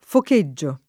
vai all'elenco alfabetico delle voci ingrandisci il carattere 100% rimpicciolisci il carattere stampa invia tramite posta elettronica codividi su Facebook focheggiare v.; focheggio [ fok %JJ o ], ‑gi — fut. focheggerò [ foke JJ er 0+ ]